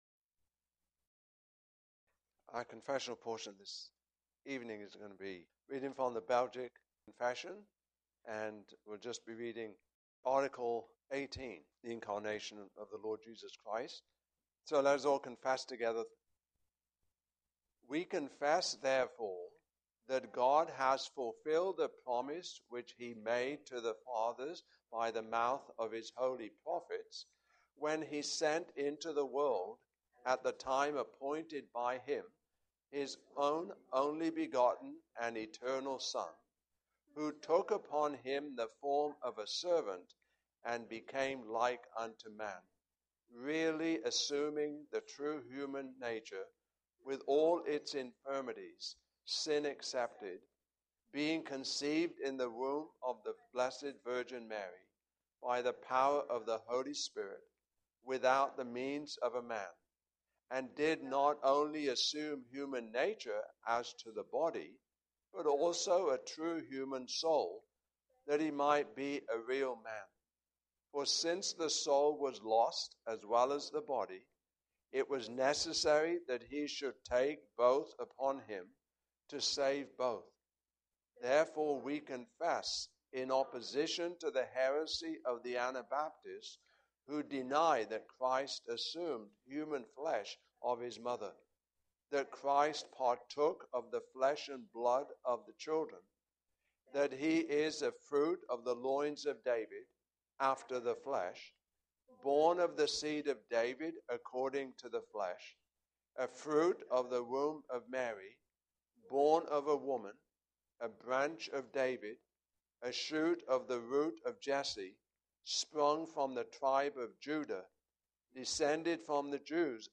Belgic Confession 2022 Passage: Philippians 2:5-11 Service Type: Evening Service Topics